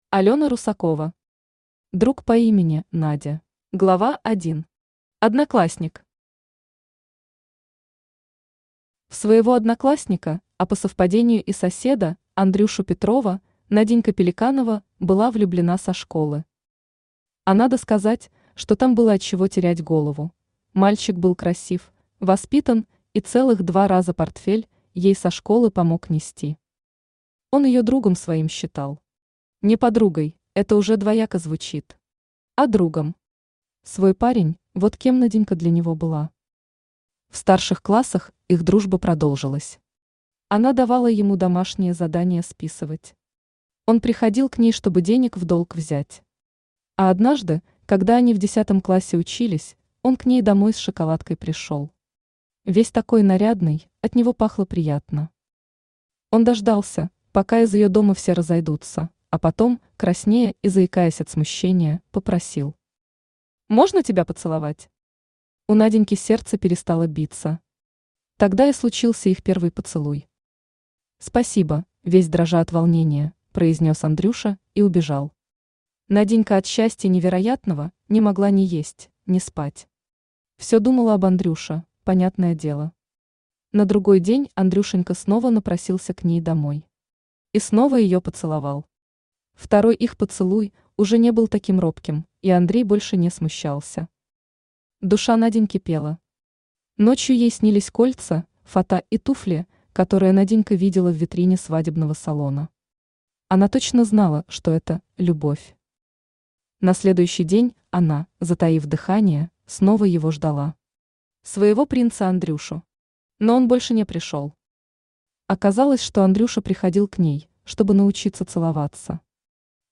Aудиокнига Друг по имени Надя Автор Алёна Русакова Читает аудиокнигу Авточтец ЛитРес.